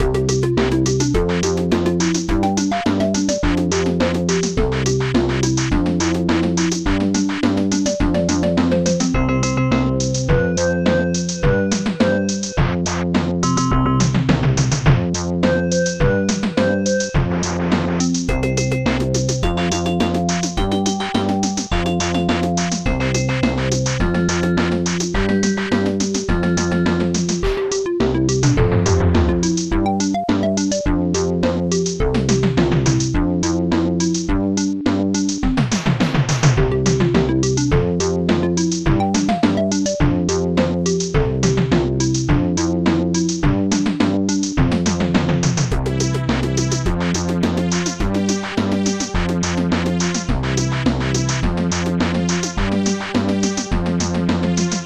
SoundTracker Module
Instruments funbass popsnare1 blast asia bassdrum1 dxbass popsnare1 popsnare1 nice celeste strange claps2 hihat2 snare2 bassdrum1